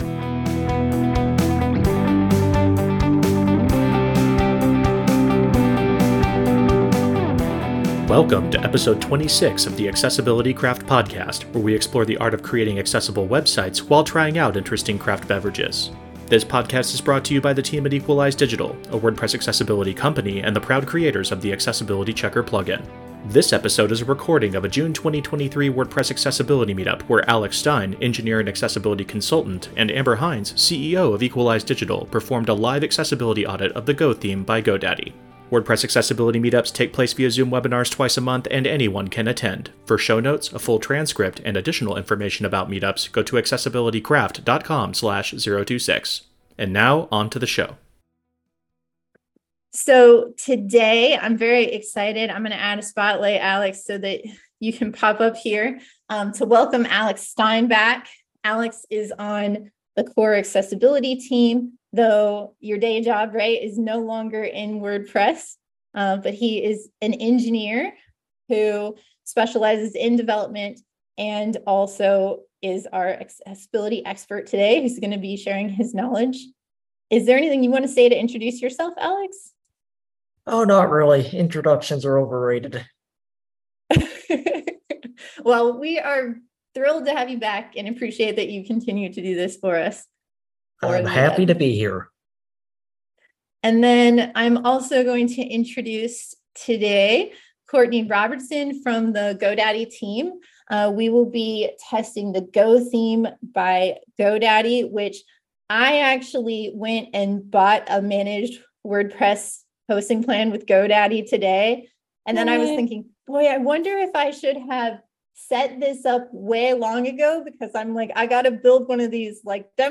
WordPress Accessibility Meetups take place via Zoom webinars twice a month, and anyone can attend.